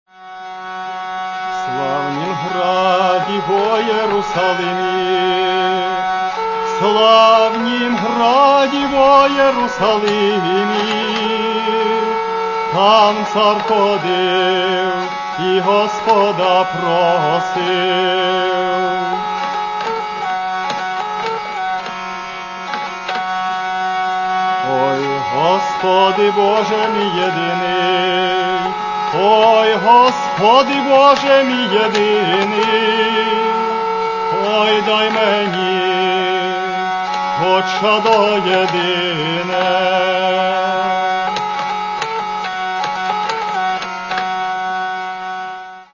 Каталог -> Народна -> Бандура, кобза тощо
(Кант)